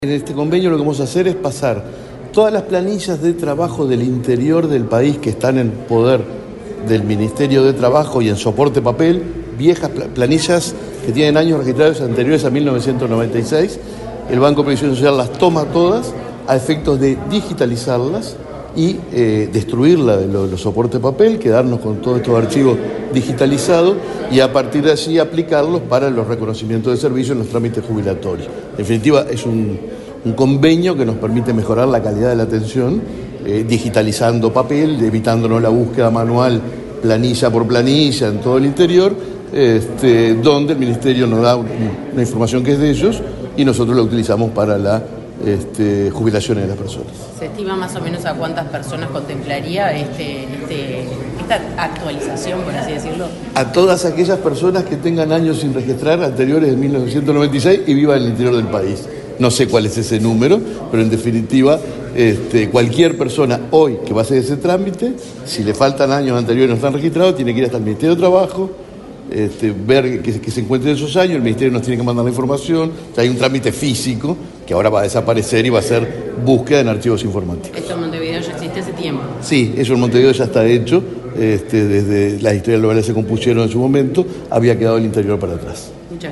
Declaraciones del presidente del BPS, Alfredo Cabrera
El presidente del Banco de Previsión Social (BPS), Alfredo Cabrera, dialogó con la prensa, antes de firmar un convenio con autoridades de la dirección